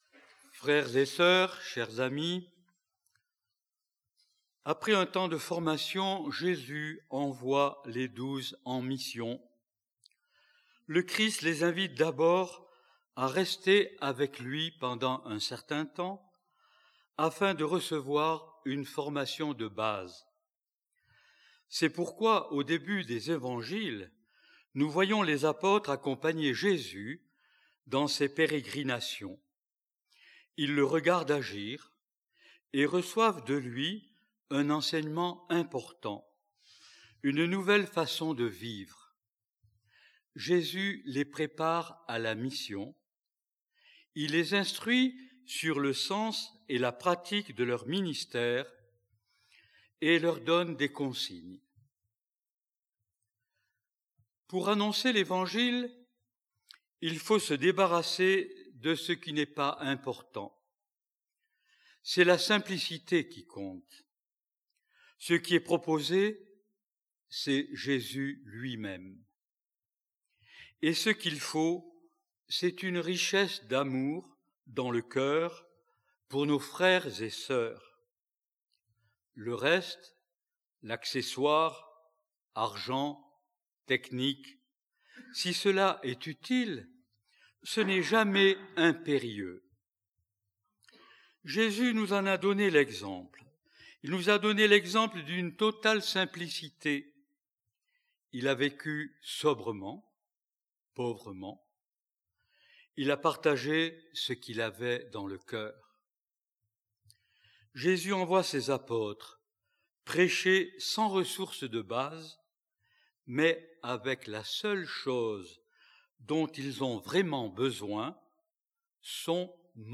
L'homélie
Grâce à un enregistrement en direct